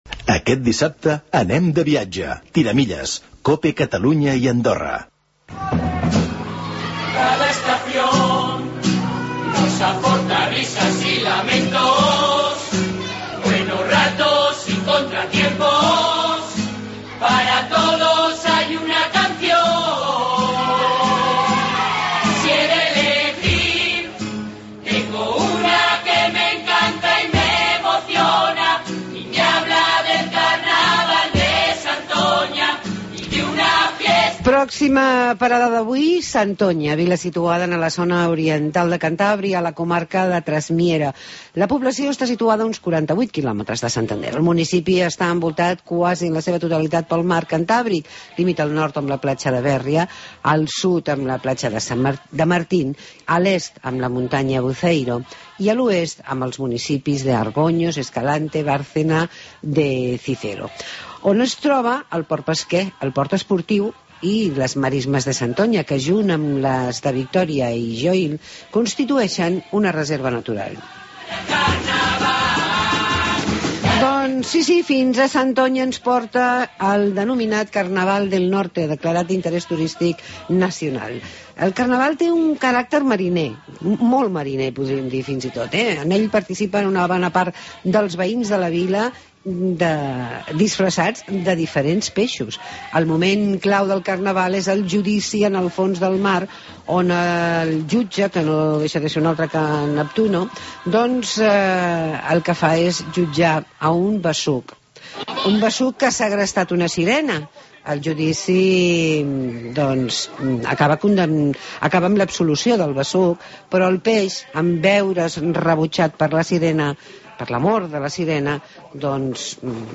Concurso de Murgas en Santoña (Cantabria)